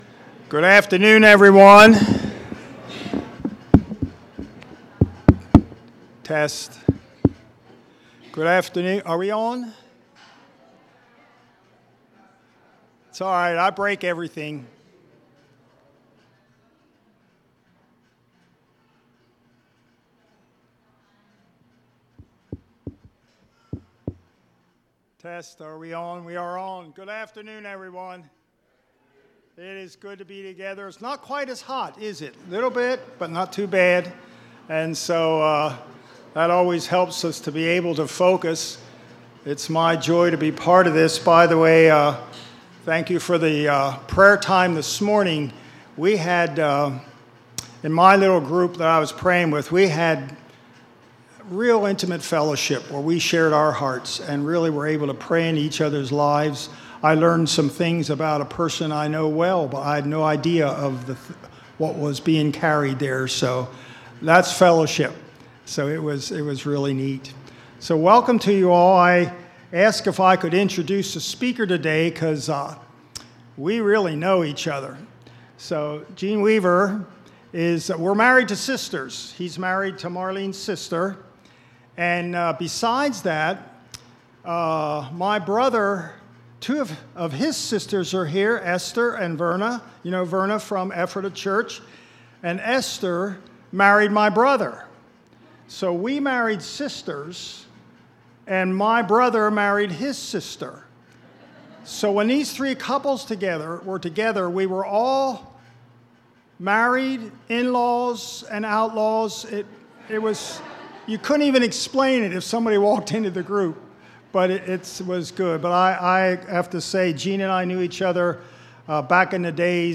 Series: Campmeeting 2025